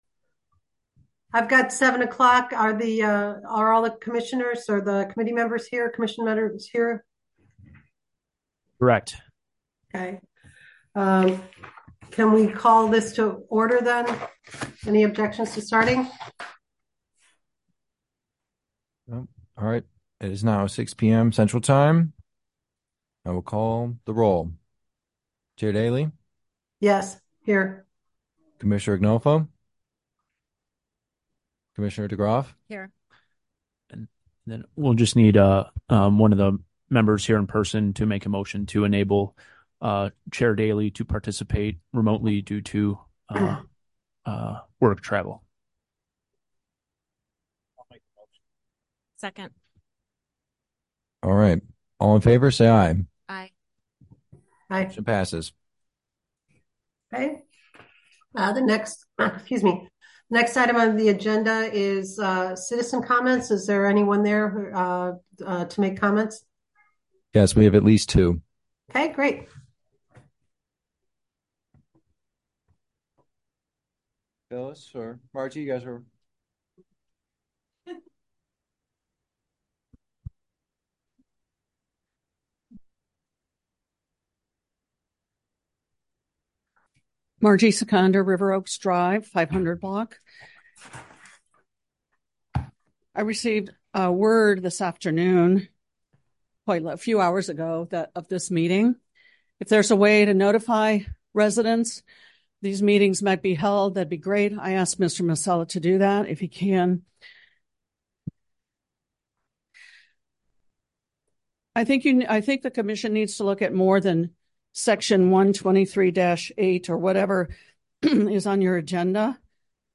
Special Meeting of the Local Ethics Commission
Village Hall - 1st Floor - COMMUNITY ROOM - 400 Park Avenue - River Forest - IL